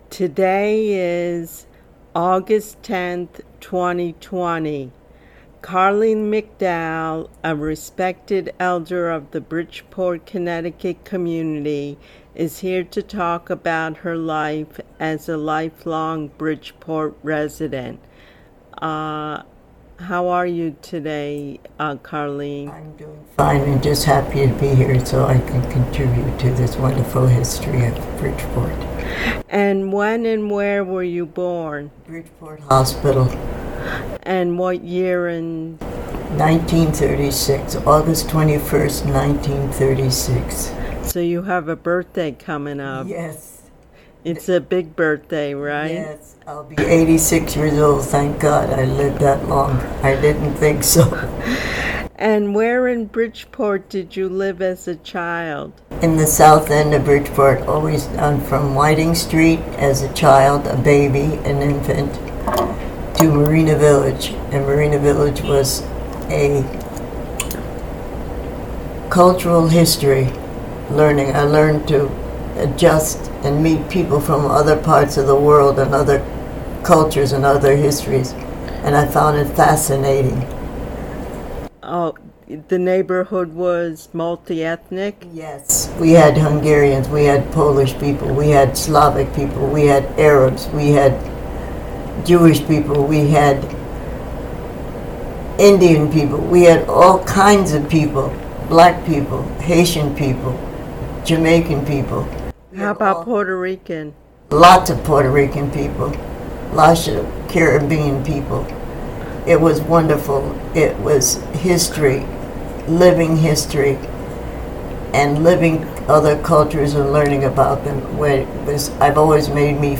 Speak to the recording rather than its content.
PLEASE NOTE: the sound on this recording is very uneven.